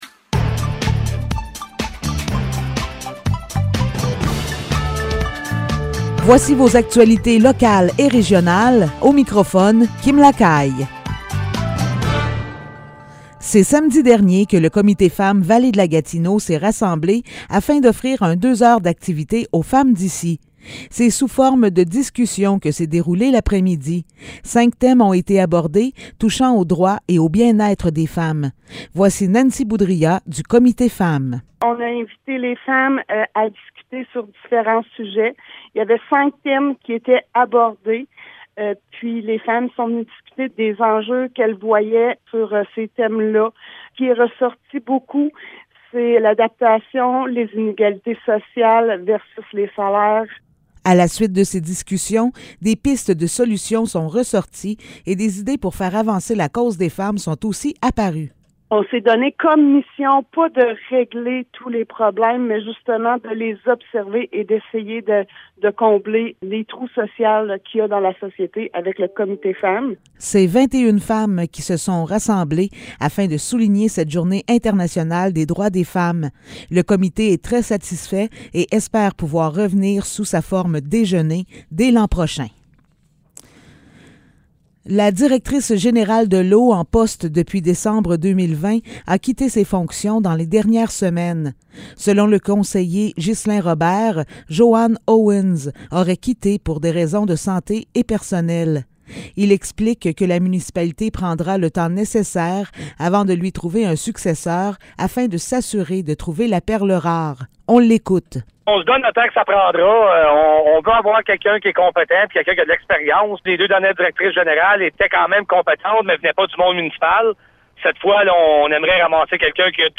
Nouvelles locales - 14 mars 2022 - 15 h